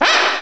Add all new cries
cry_not_tepig.aif